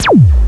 Shooting0012.ogg